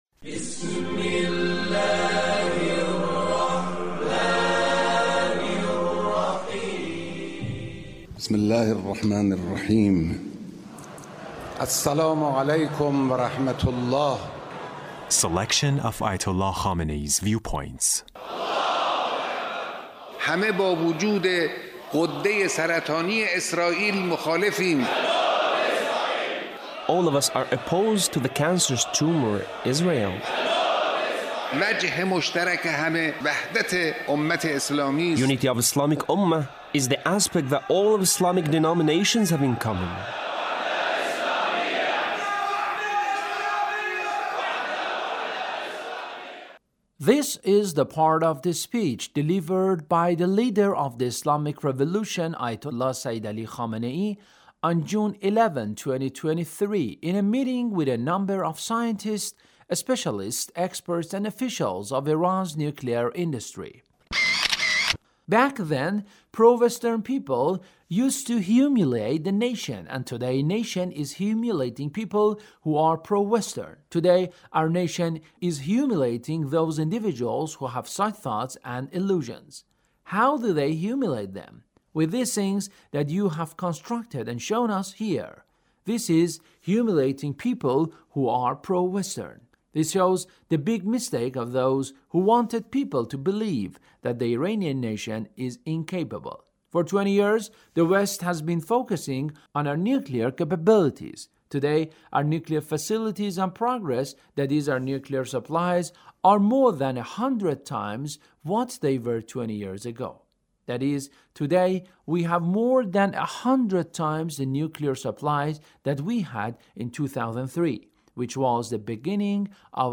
Leader's Speech (1791)
Leader's Speech in a meeting with a number of scientists,and officials of Iran’s nuclear industry